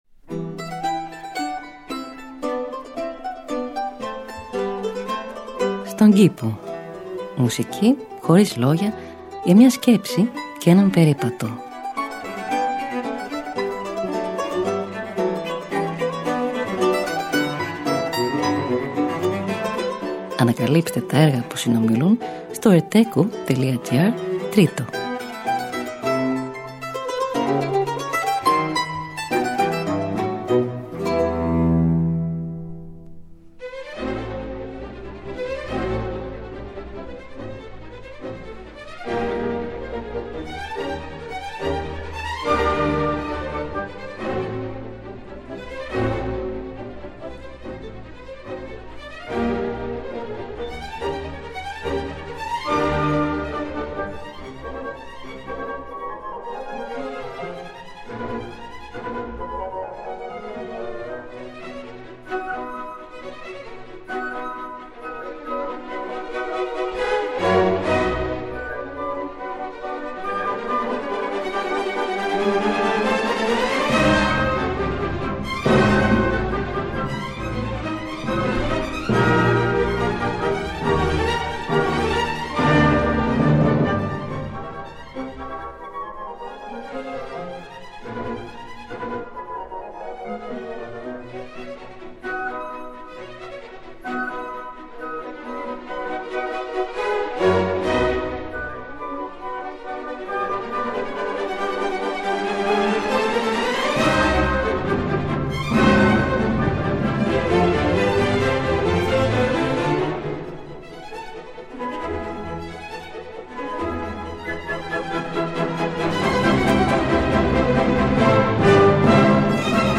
Μουσική Χωρίς Λόγια για μια Σκέψη και έναν Περίπατο.